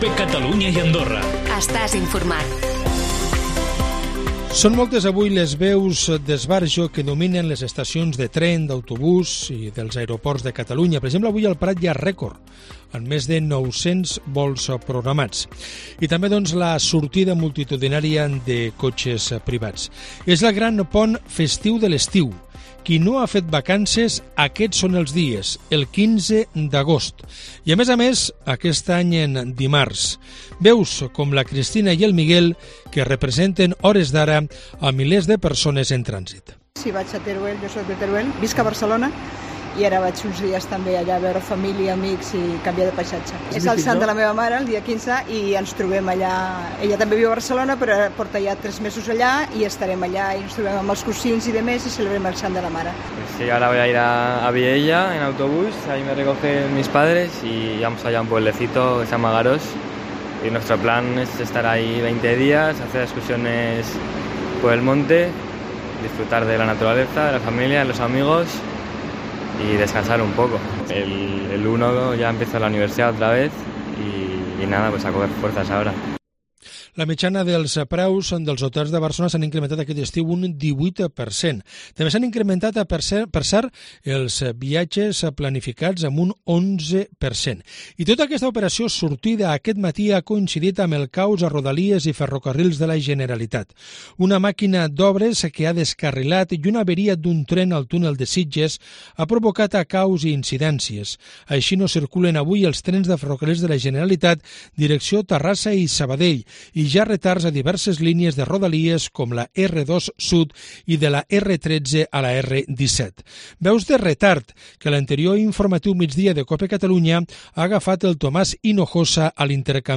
Informatiu migdia 11-08